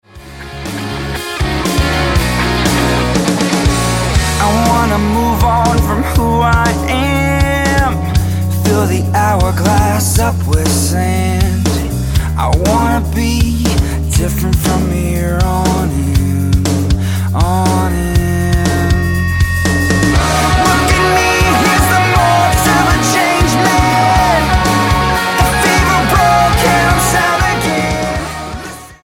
The punk rockers progress to a more pop friendly sound
Style: Rock